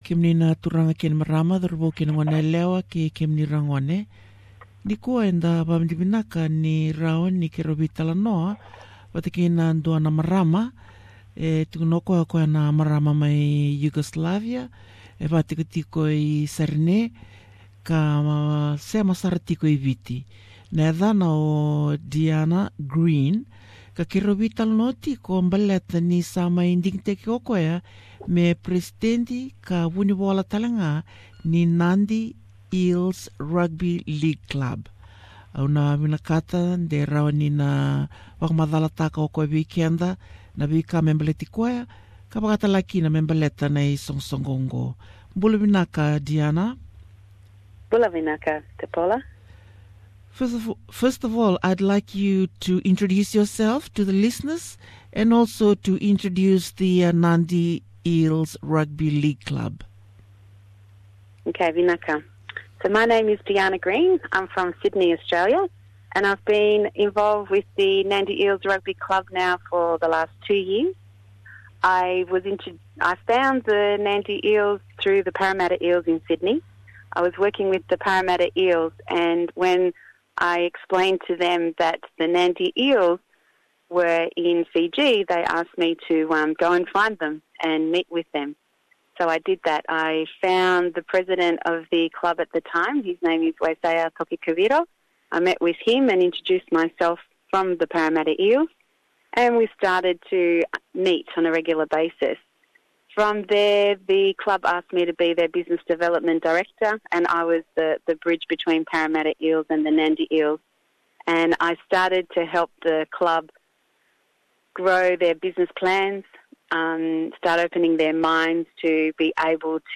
Fiji Nadi Eels Rugby League Source: SBS Radio